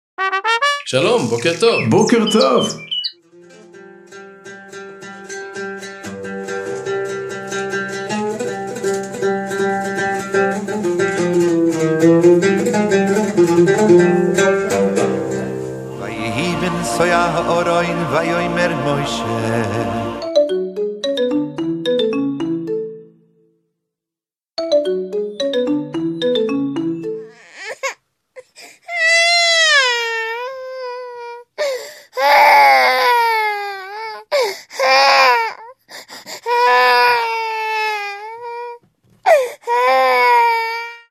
תרשו לי לעלות חידה חדשה: לקחתי אלבום כלשהו מהמוזיקה החסידית ופשוט לפי הטקסטים ושמות השירים שלו (של חלק מהשירים לא הצלחתי את הכל) יצרתי קובץ של 40 שניות עם כל מיני קולות וסאונדים שרומזים על שמות השירים.